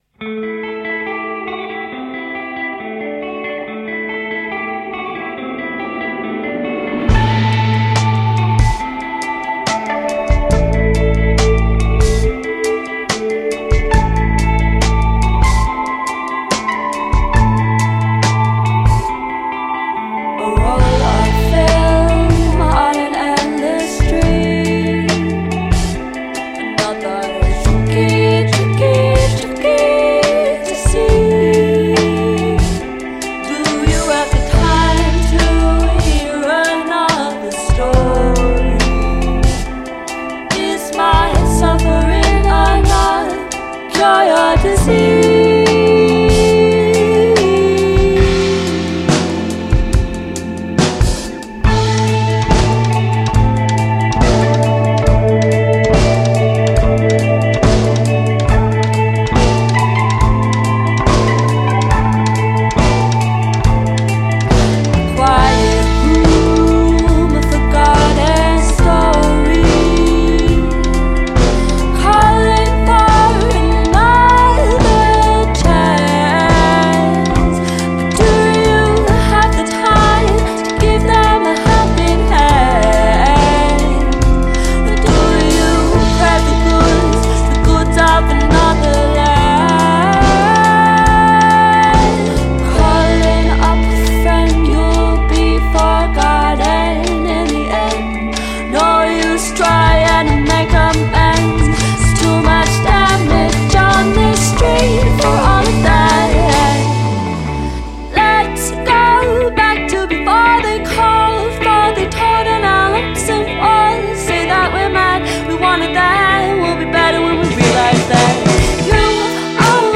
five-piece indie pop group